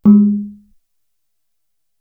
Index of /90_sSampleCDs/Best Service ProSamples vol.55 - Retro Sampler [AKAI] 1CD/Partition D/GAMELAN